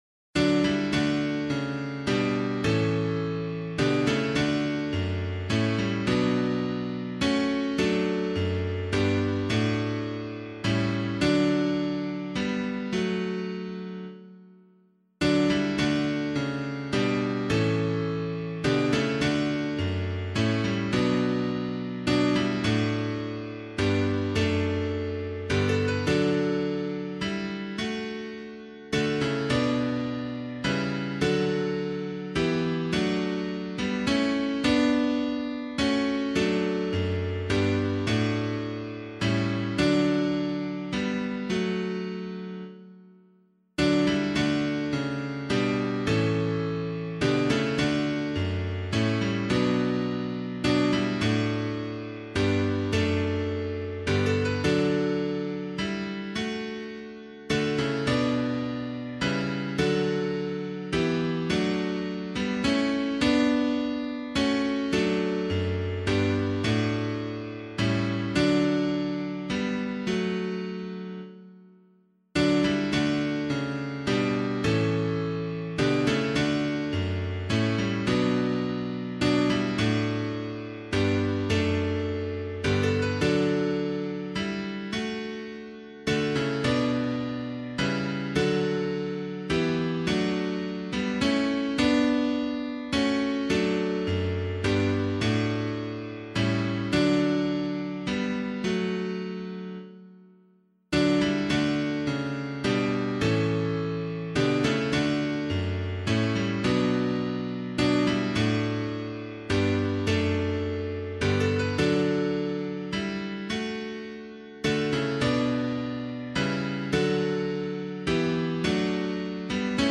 Composer:    Irish melody, ca. 18th cent.; harmonized by Charles V. Stanford, 1852-1924.
O Breathe on Me O Breath of God [Hatch - SAINT COLUMBA] - piano.mp3